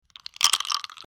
Ice Cubes In Drink
Ice_cubes_in_drink.mp3